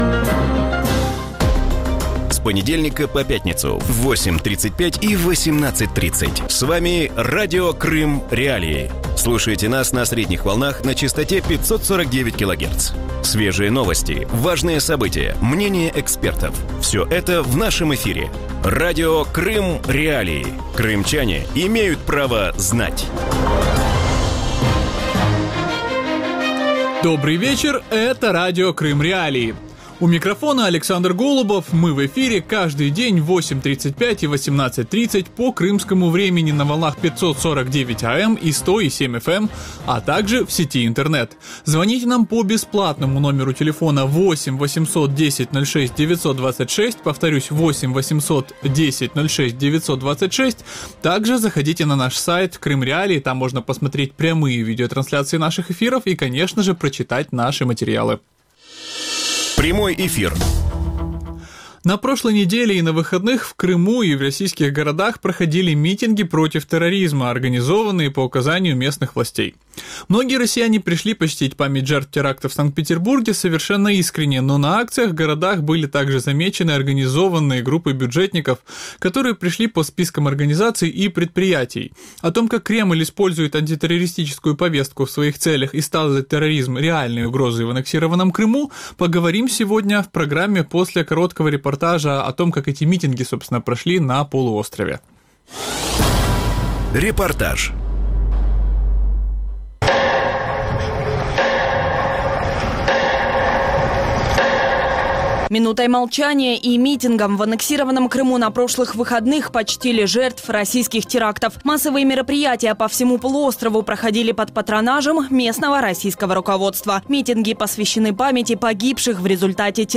У вечірньому ефірі Радіо Крим.Реалії обговорюють мітинги проти тероризму в Криму і російських містах, в яких брали участь бюджетники за списками. Як Кремль перехоплює антитерористичну повістку у опозиції? Чому російські спецслужби не можуть запобігти терактам в країні?